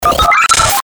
FX-1906-PHONE-BREAKER
FX-1906-PHONE-BREAKER.mp3